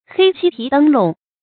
黑漆皮燈籠 注音： ㄏㄟ ㄑㄧ ㄆㄧˊ ㄉㄥ ㄌㄨㄙˊ 讀音讀法： 意思解釋： ①涂以黑漆的燈籠。